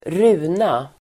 Uttal: [²r'u:na]